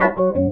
Key-organ-03_004.wav